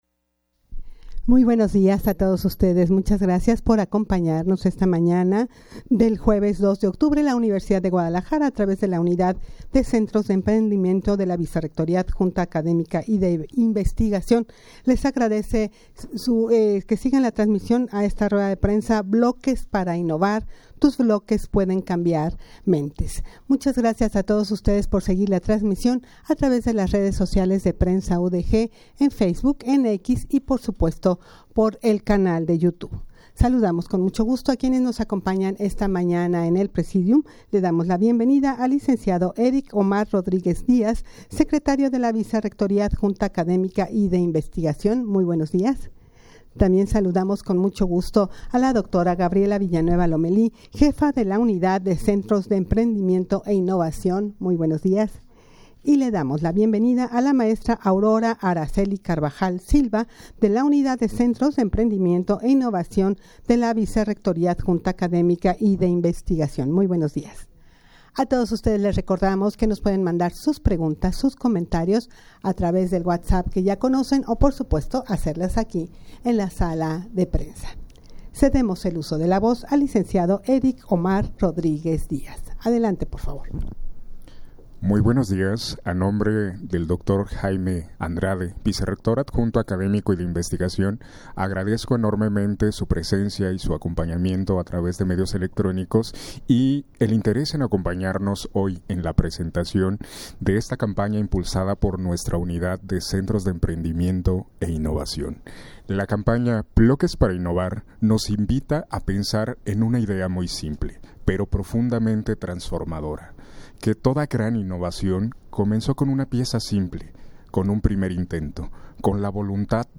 Audio de la Rueda de Prensa
rueda-de-prensa-bloques-para-innovar.-tus-bloques-pueden-cambiar-mentes-.mp3